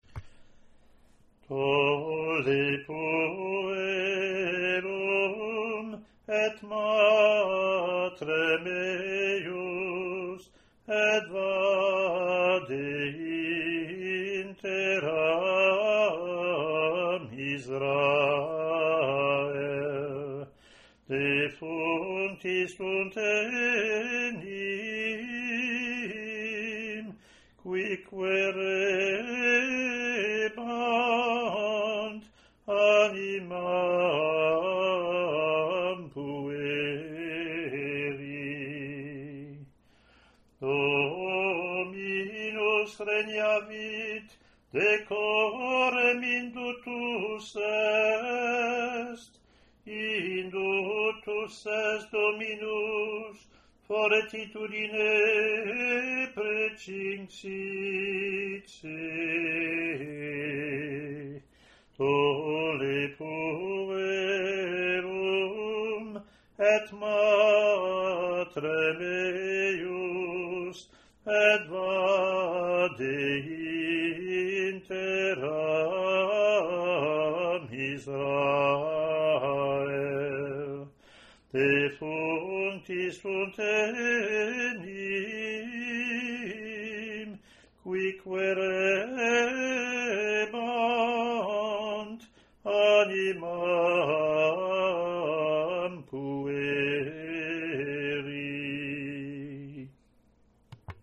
Communion (Latin antiphon+ Verse)